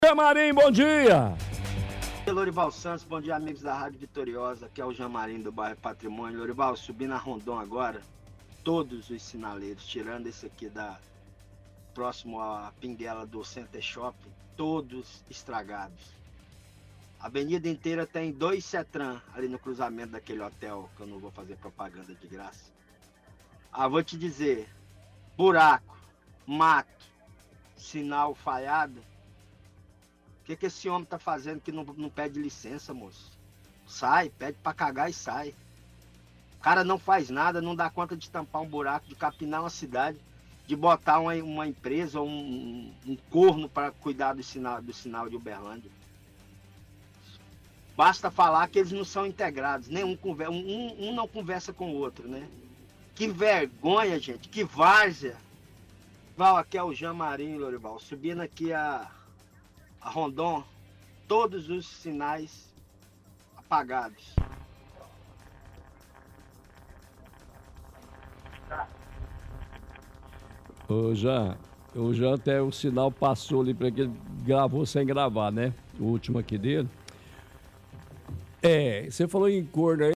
– Ouvinte reclama que os sinaleiros da avenida da Rondon não estão funcionando.